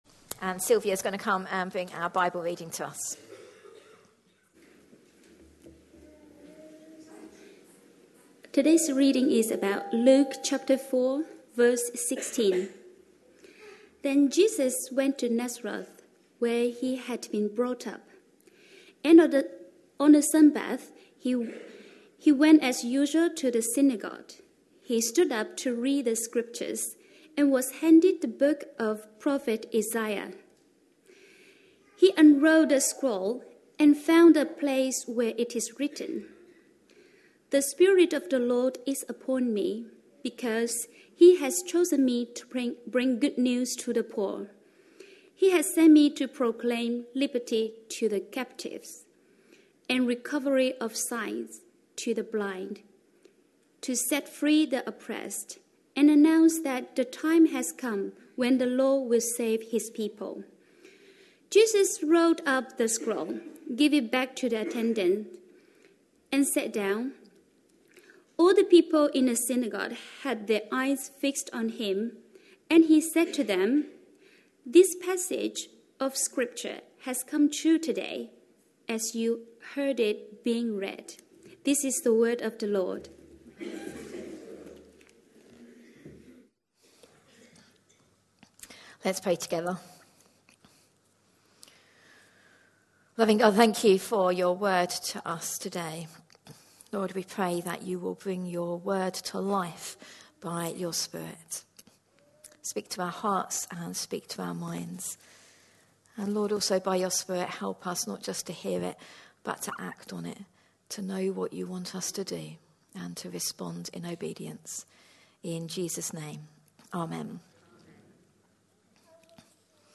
A sermon preached on 28th January, 2018, as part of our Series for early 2018. series.